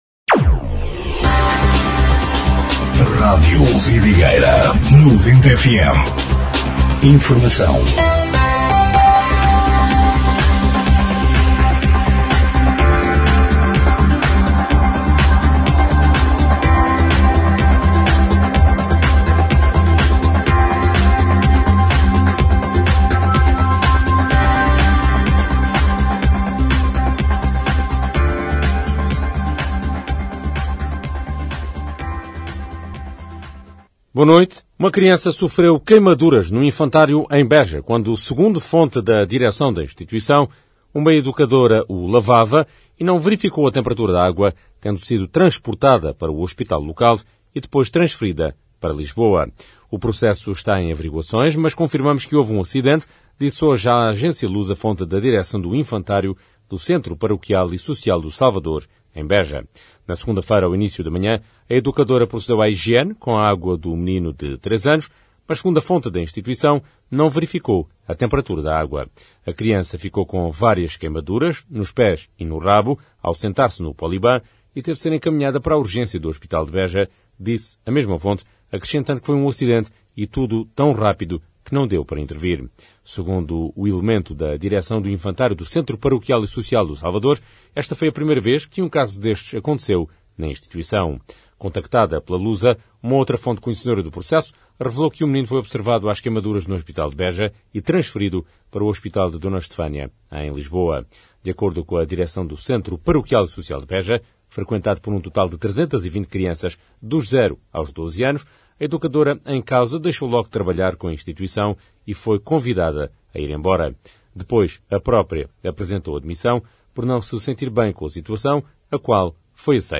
Noticiário 21/04/2026